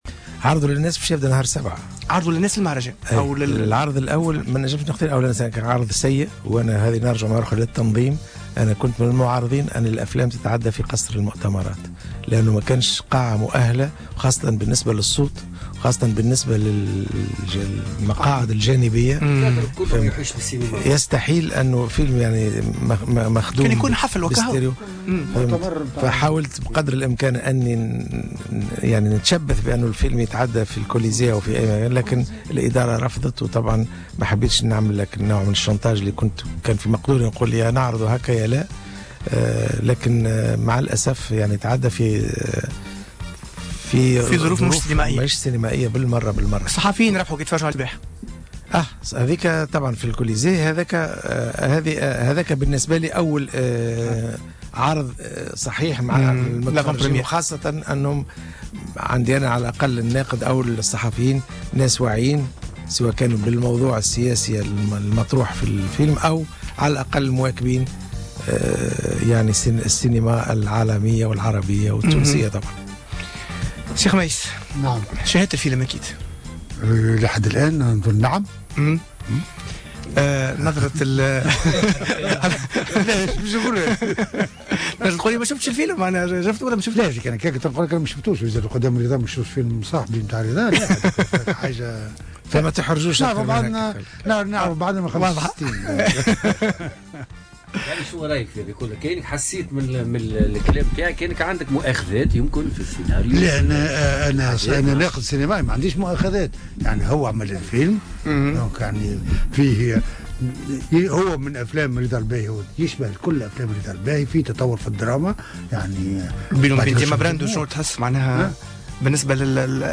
وصرح الباهي، ضيف برنامج أهل الفن على جوهرة أف أم، اليوم الجمعة، انه كان يخيّر لو تم عرض الفيلم في قاعة الكوليزي او قاعة سينمائية أخرى، غير أن ادارة المهرجان رفضت الإقتراح، مضيفا انه رفض الدخول في مهاترات مع هذه الاخيرة أياما قليلة قبل الافتتاح.